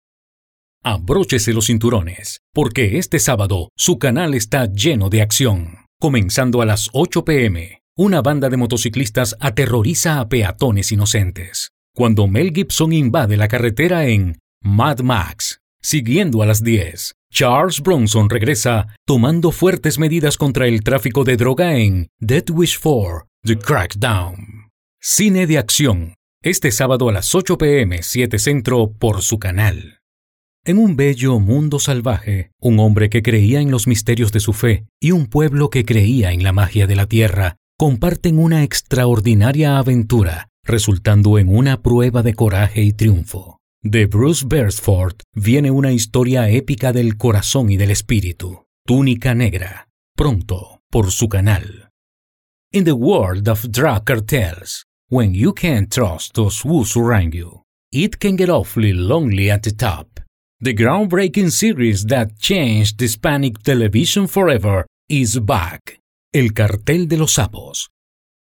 Locutor profesional venezolano con manejo de acento neutro del español latinoamericano, voice over talent
Sprechprobe: Sonstiges (Muttersprache):